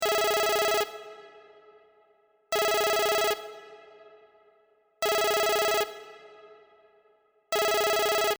In the movie, the ringtone has a slightly higher pitch.